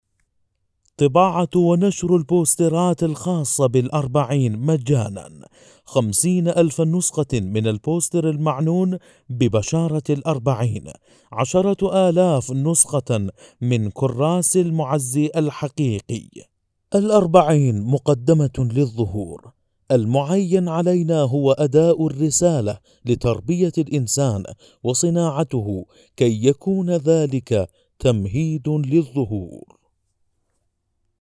Narration
Male
Adult